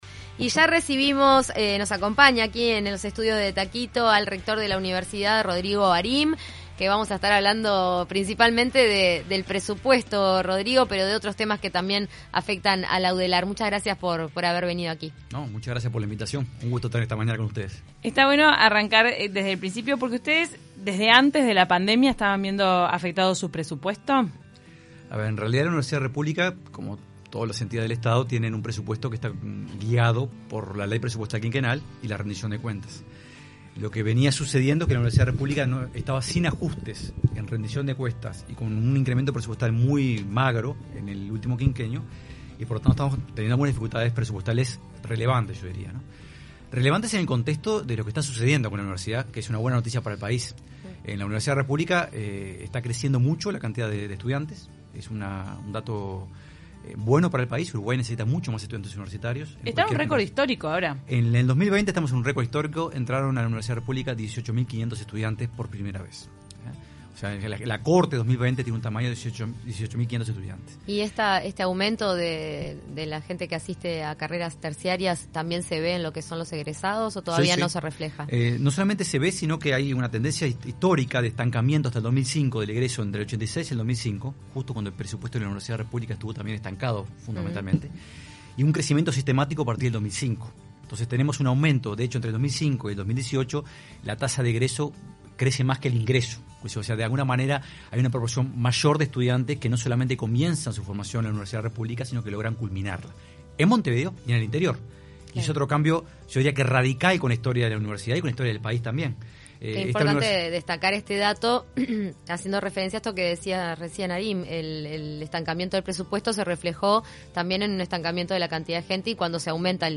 El rector de la Udelar, Rodrigo Arim, dijo en De Taquito que muchas carreras universitarias van a caer y otras perderán calidad.